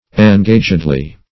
Meaning of engagedly. engagedly synonyms, pronunciation, spelling and more from Free Dictionary.
engagedly - definition of engagedly - synonyms, pronunciation, spelling from Free Dictionary Search Result for " engagedly" : The Collaborative International Dictionary of English v.0.48: Engagedly \En*ga"ged*ly\, adv.